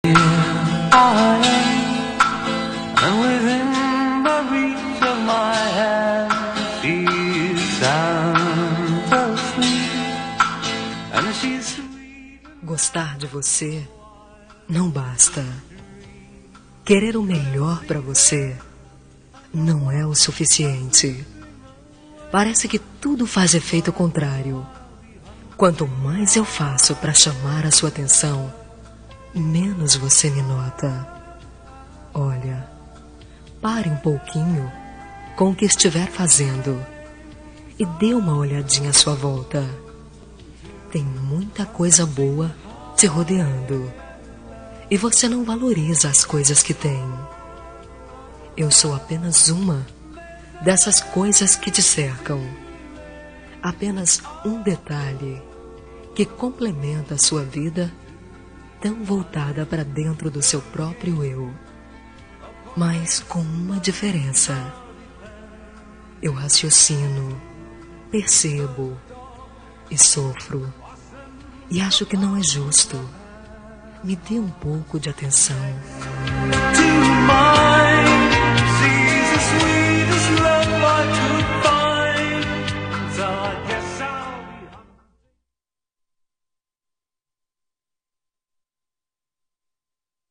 Toque para Não Terminar – Voz Feminina – Cód: 462 Me dê Atenção